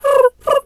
Animal_Impersonations
pigeon_2_emote_06.wav